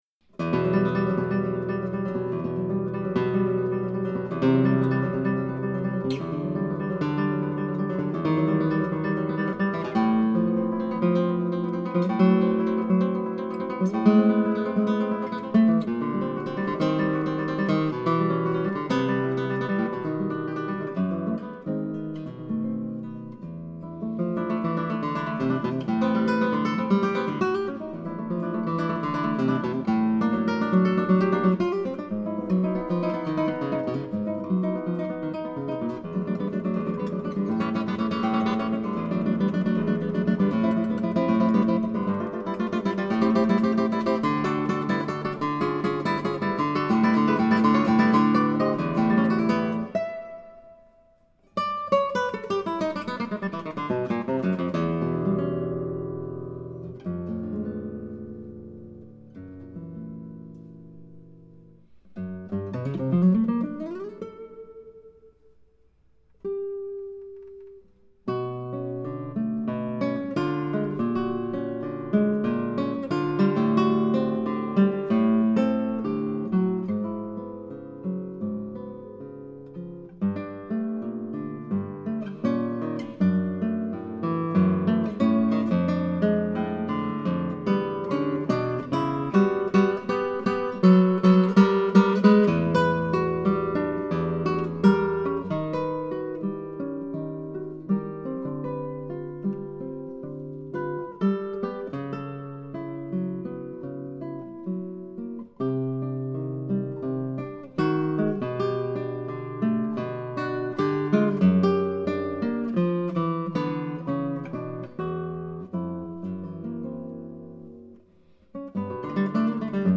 Tags: Violão Solo